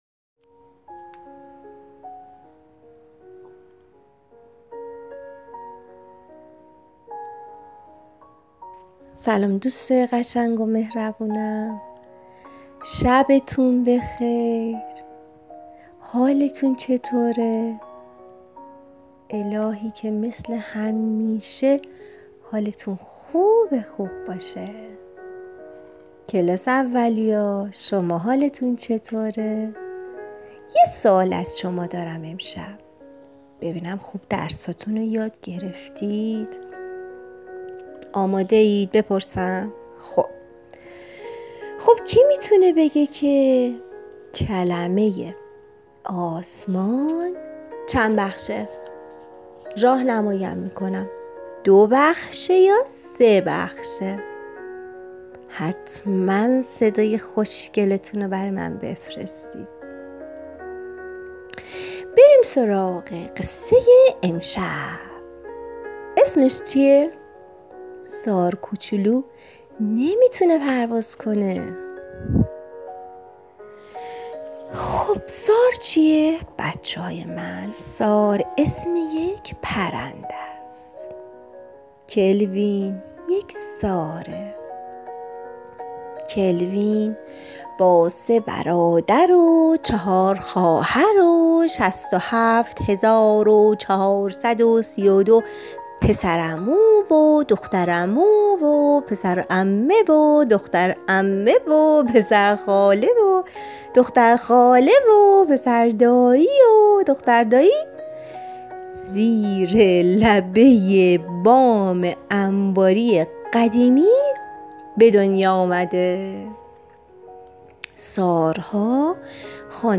قصه صوتی کودکان دیدگاه شما 103 بازدید